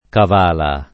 Cavala [ kav # la ] → Cavalla